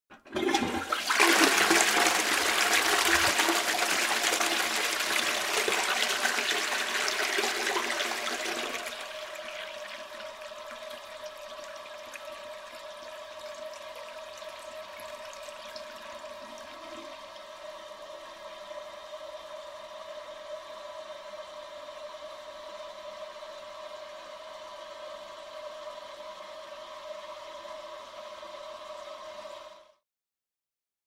Шум воды при сливе в туалете